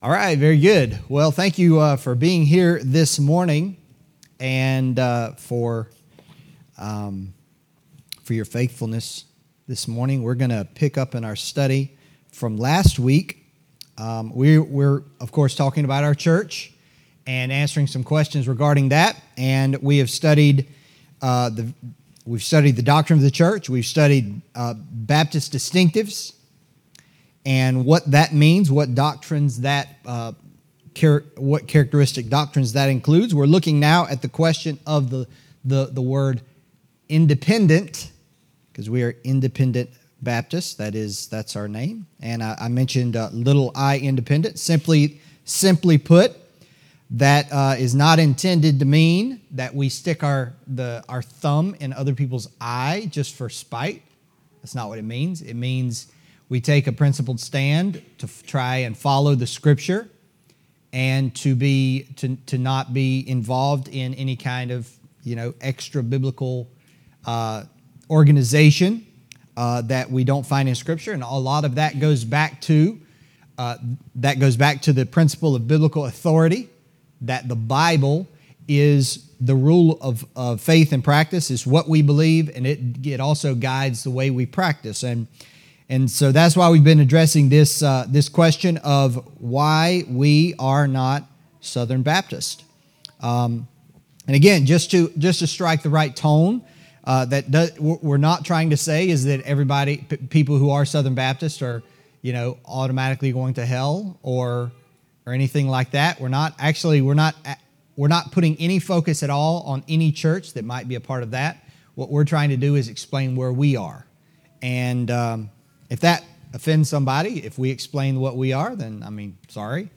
Adult Sunday School: Our Church &middot